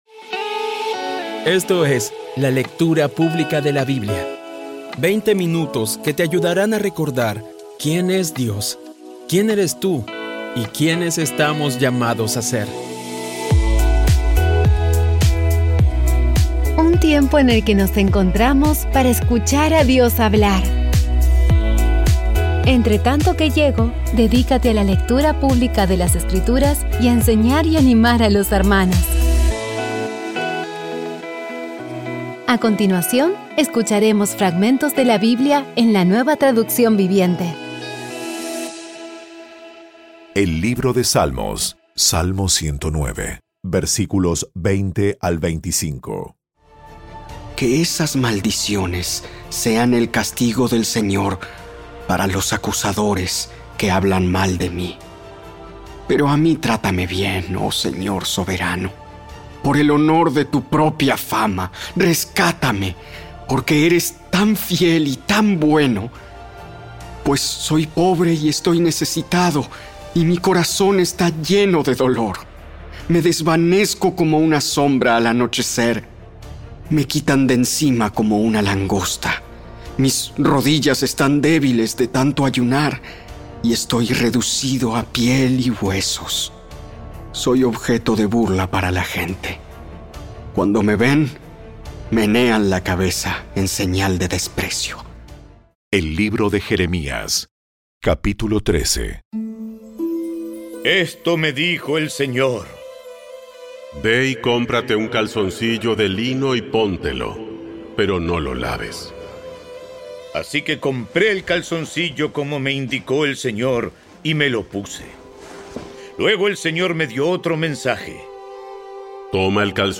Audio Biblia Dramatizada por CVCLAVOZ / Audio Biblia Dramatizada Episodio 277
Poco a poco y con las maravillosas voces actuadas de los protagonistas vas degustando las palabras de esa guía que Dios nos dio.